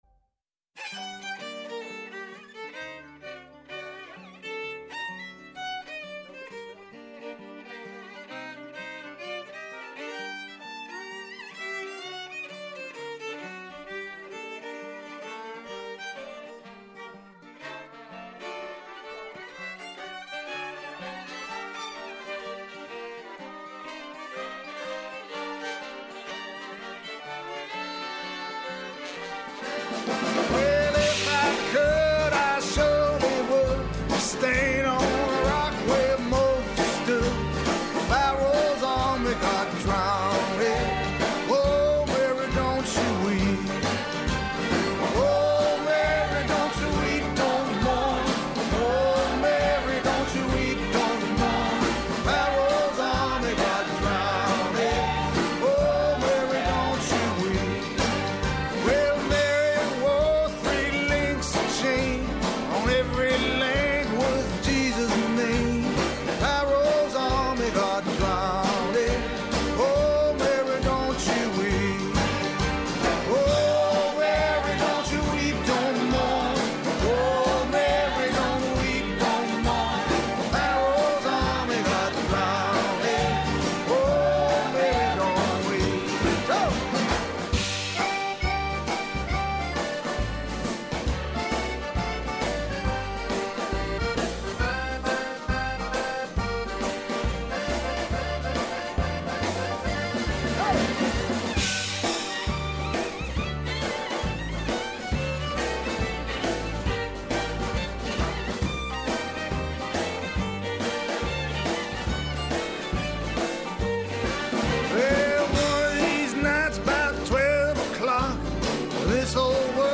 Άνθρωποι της επιστήμης, της ακαδημαϊκής κοινότητας, πολιτικοί, ευρωβουλευτές, εκπρόσωποι Μη Κυβερνητικών Οργανώσεων και της Κοινωνίας των Πολιτών συζητούν για όλα τα τρέχοντα και διηνεκή ζητήματα που απασχολούν τη ζωή όλων μας από την Ελλάδα και την Ευρώπη μέχρι την άκρη του κόσμου.